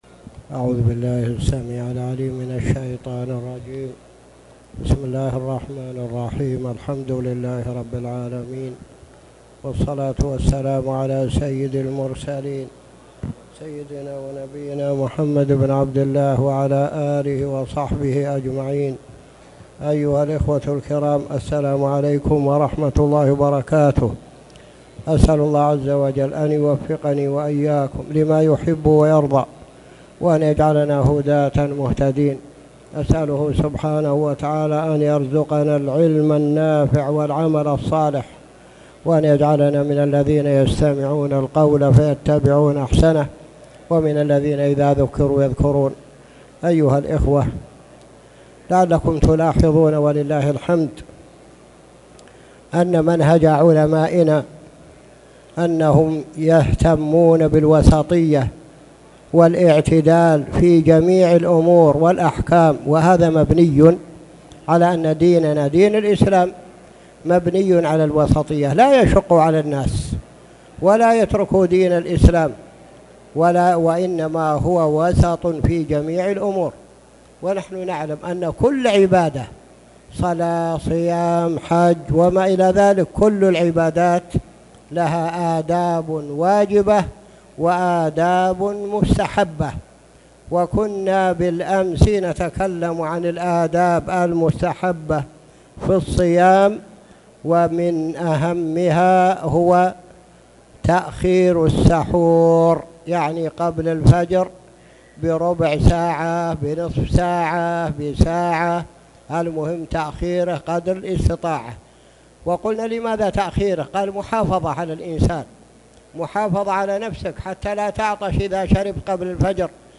تاريخ النشر ٤ شعبان ١٤٣٨ هـ المكان: المسجد الحرام الشيخ